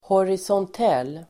Ladda ner uttalet
horisontell adjektiv, horizontal Uttal: [horisånt'el:] Böjningar: horisontellt, horisontella Synonymer: horisontal, i våg, liggande, vågrät Definition: som går längs med horisonten, vågrät (in the plane of the horizon)